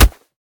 kick1.wav